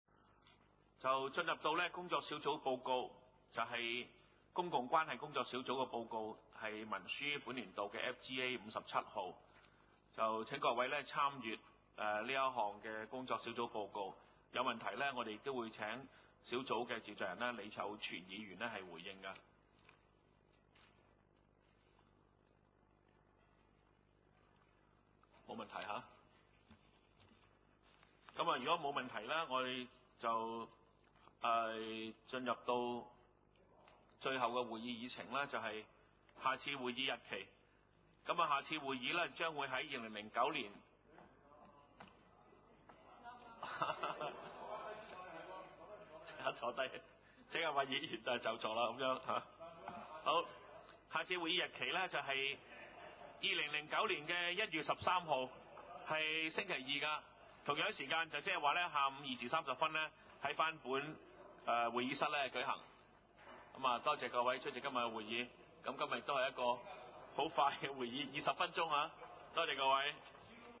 : 沙田區議會會議室